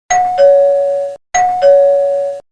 sonnette.mp3